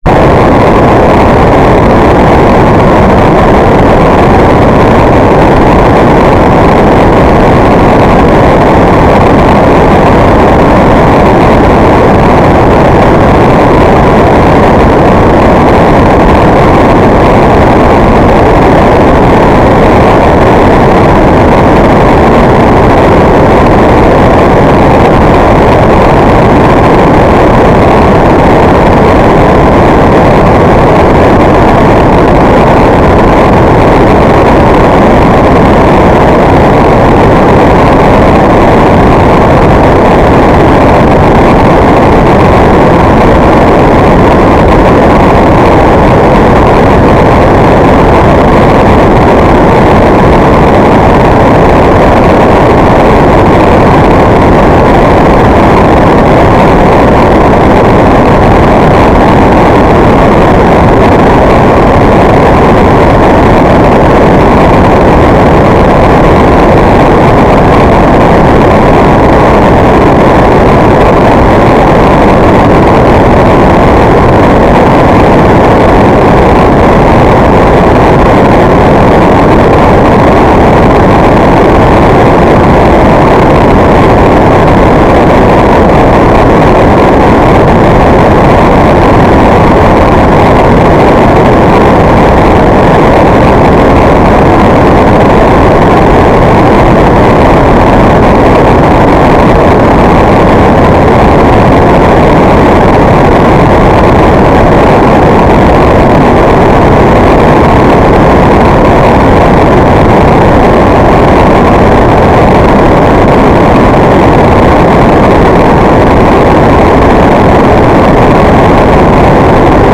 "transmitter_mode": "FSK AX.100 Mode 5",